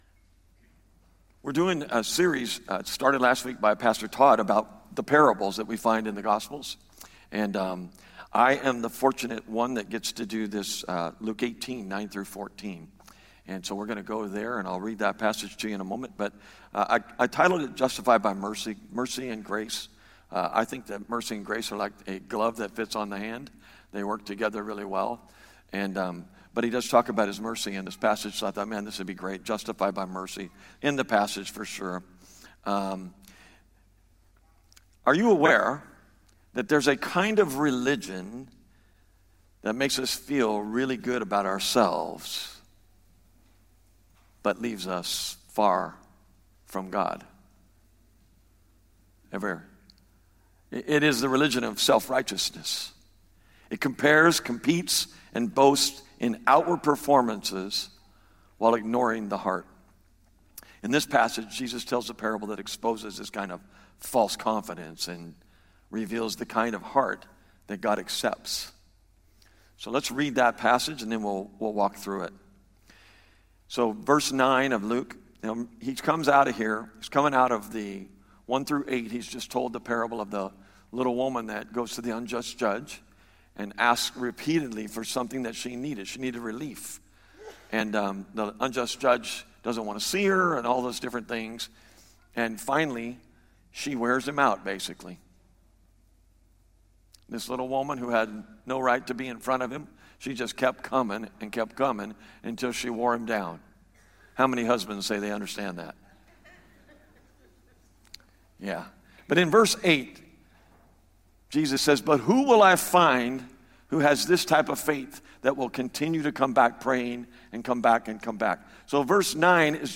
Valley Bible Church Sermons (VBC) - Hercules, CA